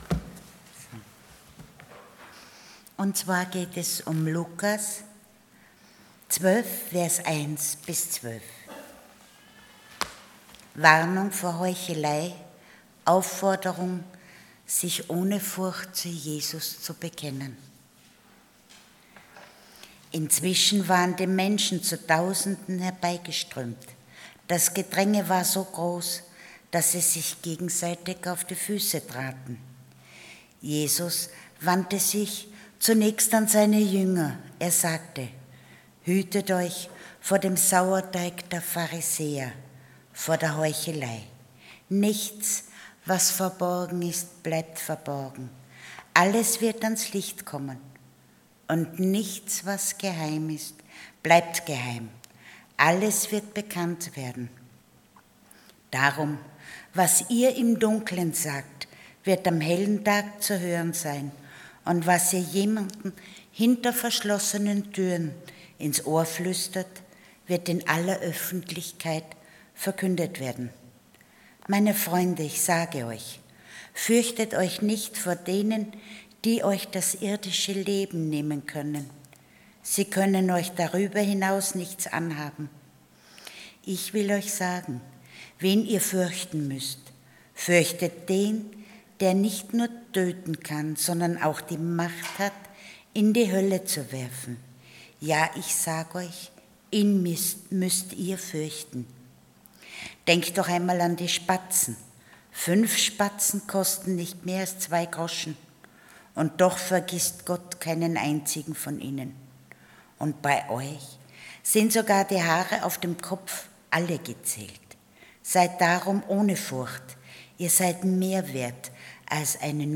Passage: Luke 12:1-12 Dienstart: Sonntag Morgen